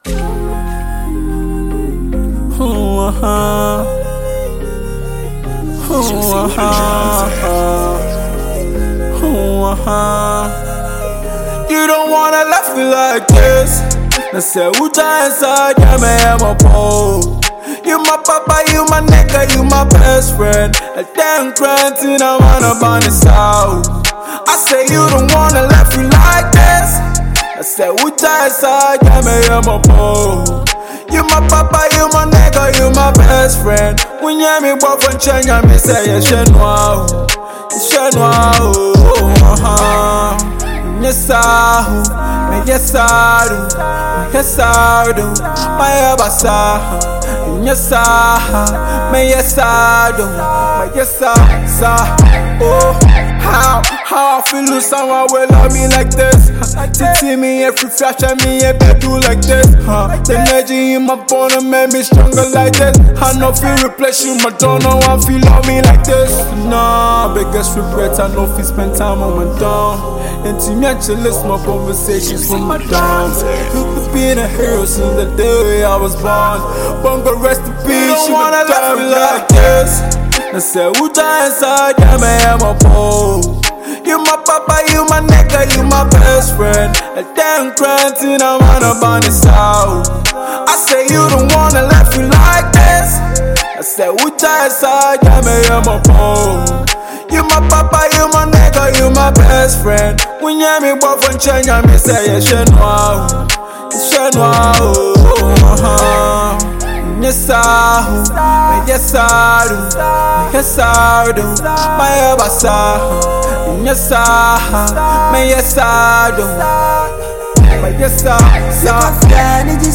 Ghanaian hip hop